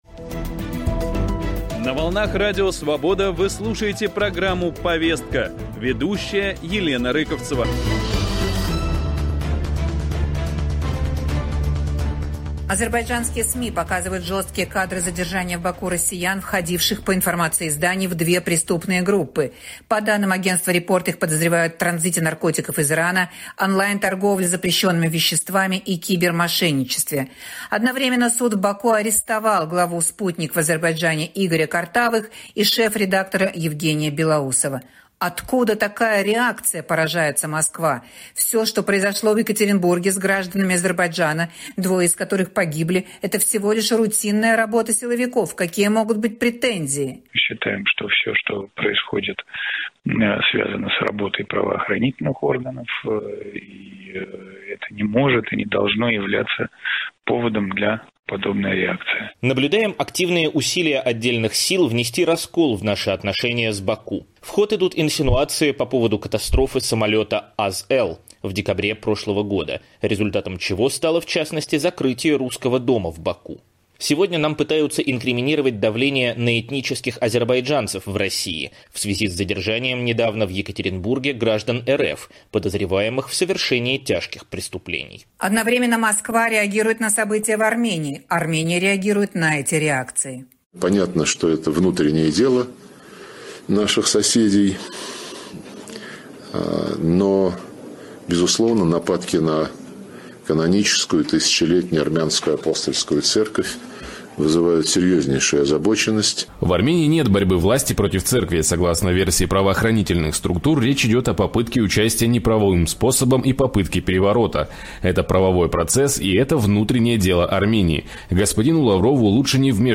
Чем похожи армянский и азербайджанский списки россиян, забаненных для въезда? В дискуссии участвуют